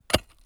suppression de la reverb sur les sfx de pioche
pickaxe_3.wav